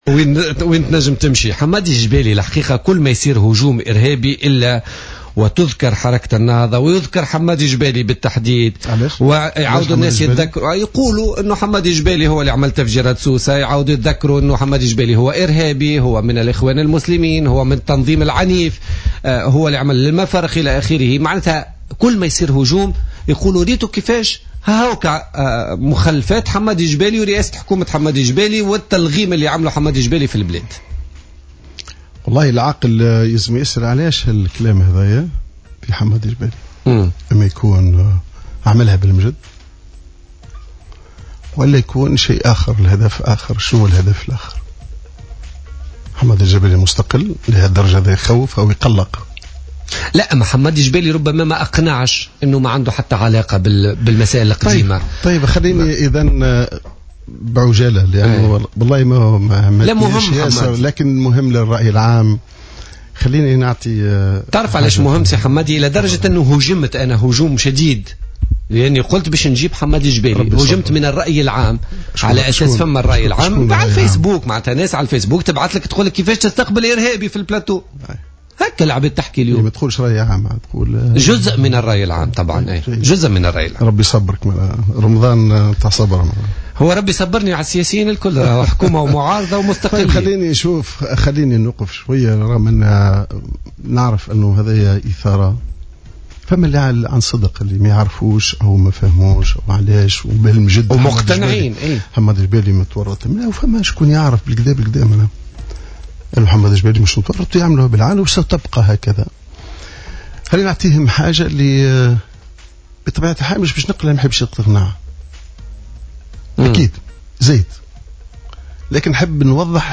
قال القيادي السابق بحركة النهضة حمادي الجبالي ضيف بوليتيكا اليوم الخميس 2 جويلية 2015 ان من يريد اثبات تورطه في تفجيرات نزل سوسة والمنستير سنة 1986 عليه ان يتجه الى أرشيف وزارة الداخلية لإثبات ذلك.